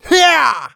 XS发力05.wav
XS发力05.wav 0:00.00 0:00.77 XS发力05.wav WAV · 66 KB · 單聲道 (1ch) 下载文件 本站所有音效均采用 CC0 授权 ，可免费用于商业与个人项目，无需署名。
人声采集素材